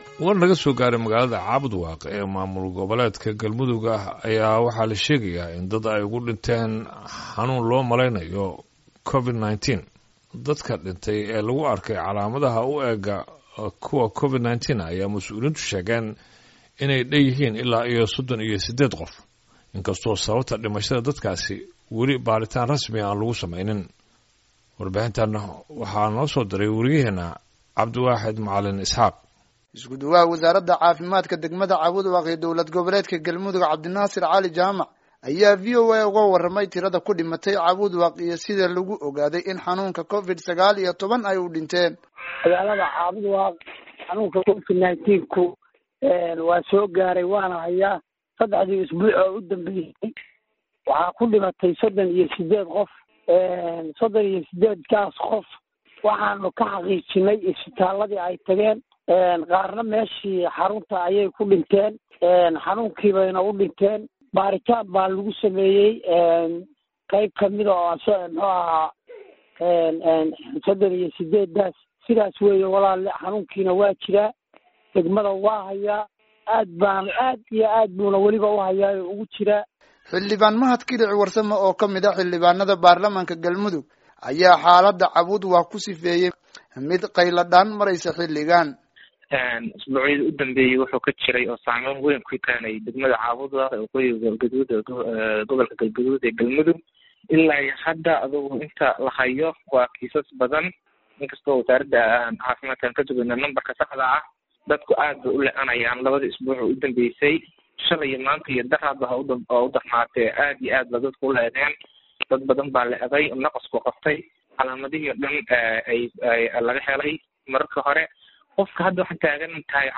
Halkan ka dhageyso warbixinta Cabudwaaq